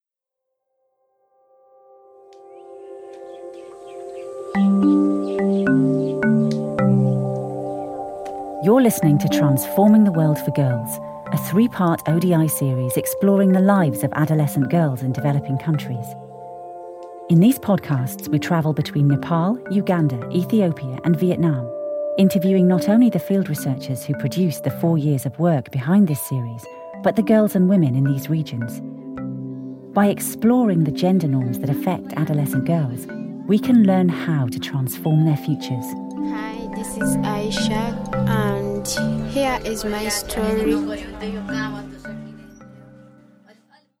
British English Female Voice Over Artist
Female
Assured, Authoritative, Confident, Corporate, Engaging, Friendly, Gravitas, Reassuring, Soft, Warm, Witty
Clients have told me that my voice hits a sweet spot - authoritative, knowledgeable and intelligent, while still being warm and approachable.
2019DryReads.mp3
Microphone: Audio Technica AT2030